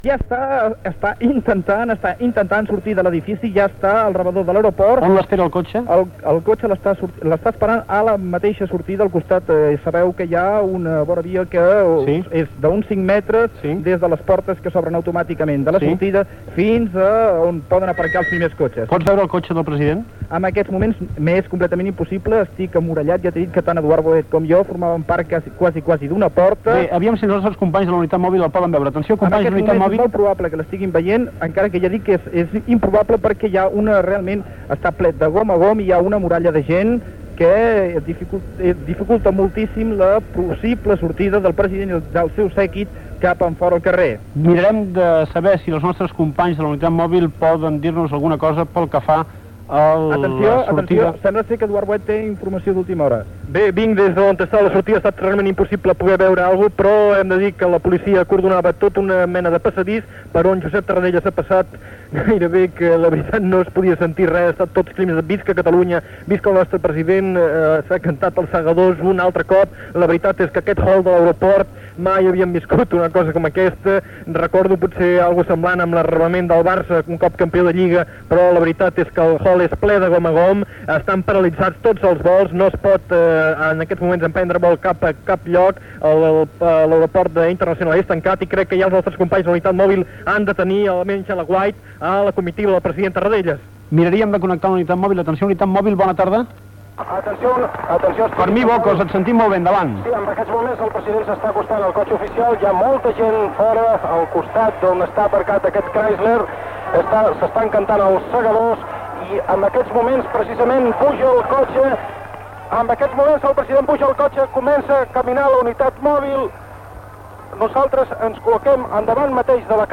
Transmissió del retorn de l'exili del president de la Generalitat Josep Tarradellas a la ciutat de Barcelona. Sortida del president Tarradellas de l'aeroport, comitiva en direcció a Barcelona.
Ambient a Montjuïc i a la Plaça de Sant Jaume. Connexió amb la caravana que passa per Bellvitge.
La unitat mòbil ja està a Plaça Espanya.